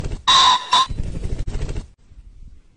雉鸡鸣叫并扇动翅膀 环颈雉鸣叫声